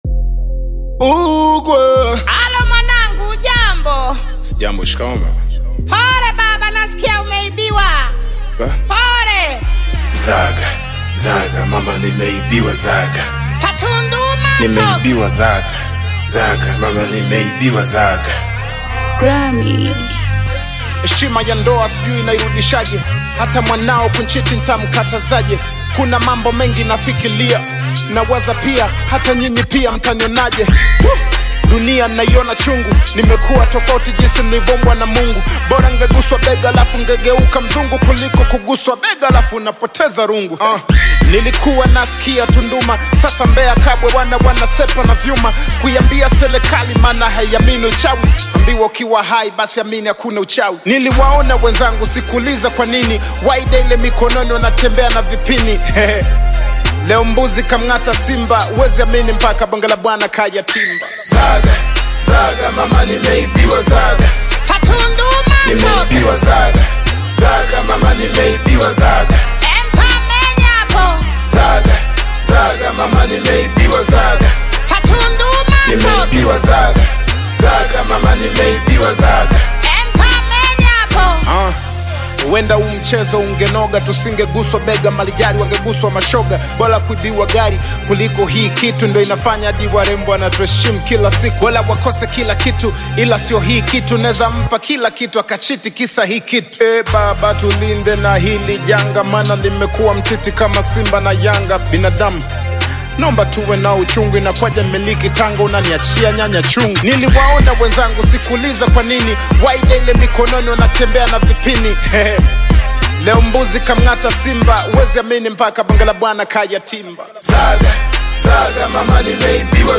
Genre: Afro Pop, Foreign Songs